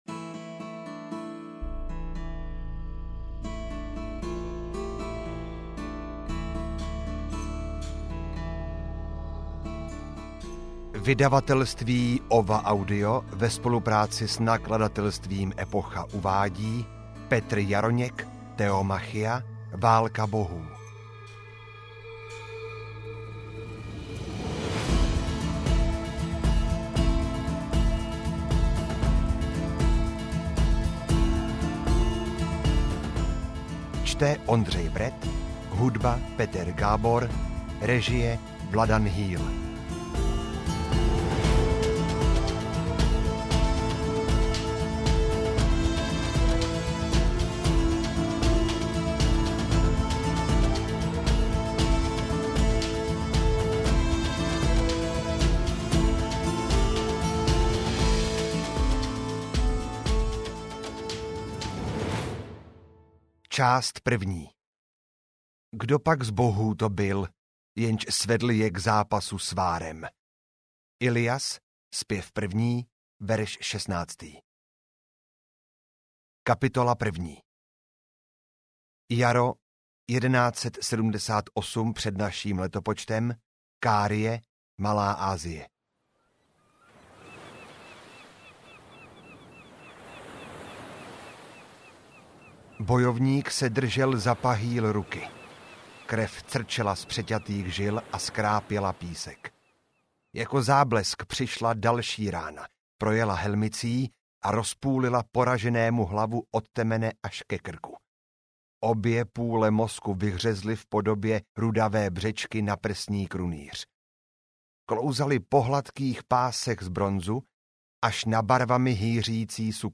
Theomachia: Válka bohů audiokniha
Ukázka z knihy